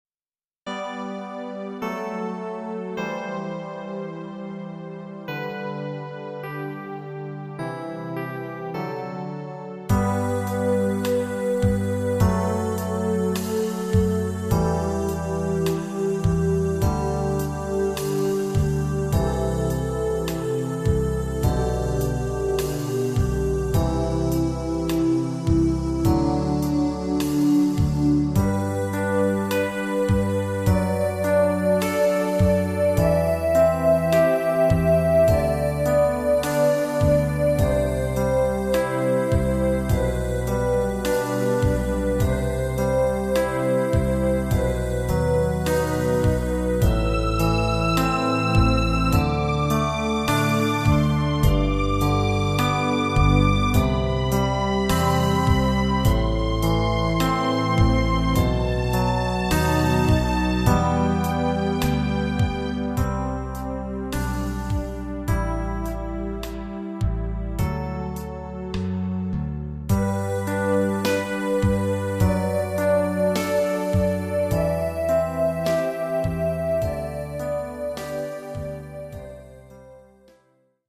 Home : Dwarsfluit :
-alle begeleidingen in een LANGZAAM en een NORMAAL tempo